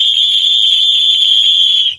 Whistle Blow
Whistle Blow is a free sfx sound effect available for download in MP3 format.
070_whistle_blow.mp3